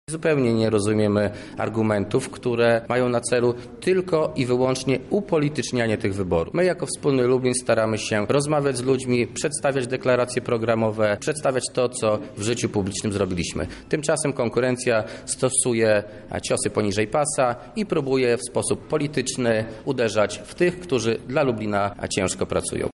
Tak sprawę komentuje radny Marcin Nowak z klubu Wspólny Lublin.